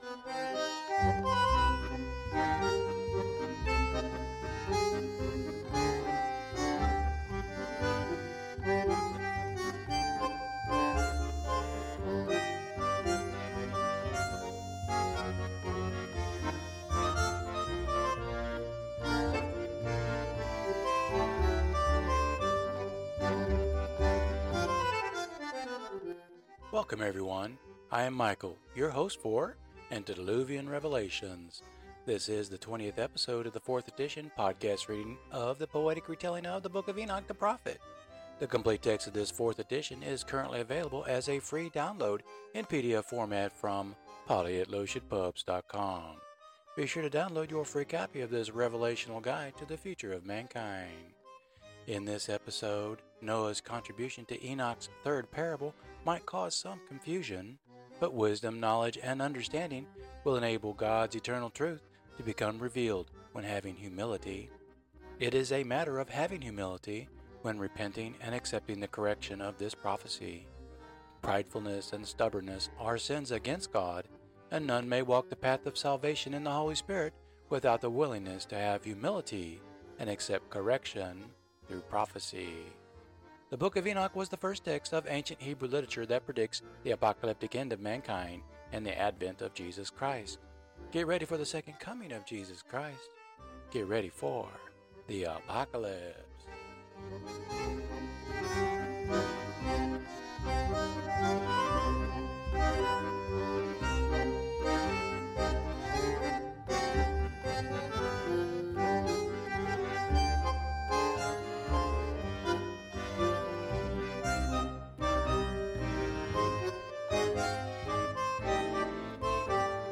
This is the twentieth episode in this fourth edition of the podcast reading of the poetry within the book. These episodes will present Part Two of the epic poem, and the music selection will get a Jazz and Rock infusion.